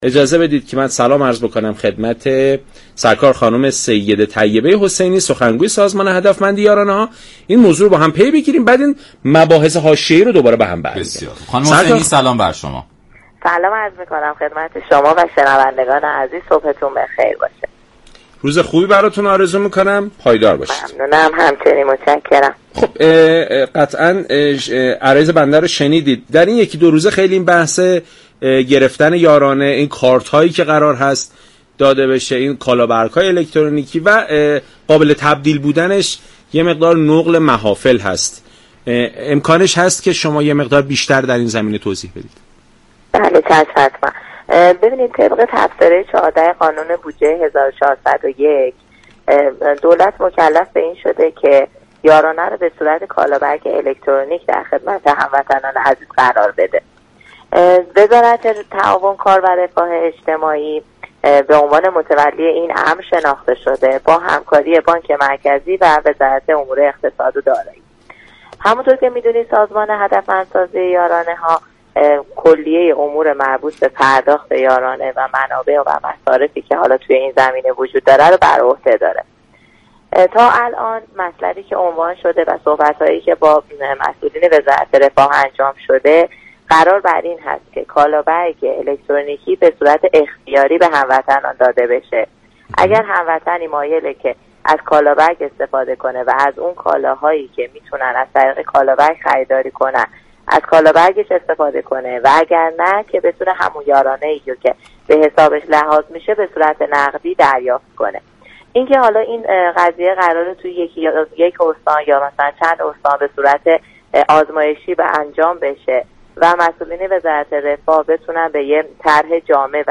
برنامه «سلام صبح بخیر» شنبه تا چهارشنبه هر هفته ساعت 6:35 از رادیو ایران پخش می شود.